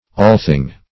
allthing - definition of allthing - synonyms, pronunciation, spelling from Free Dictionary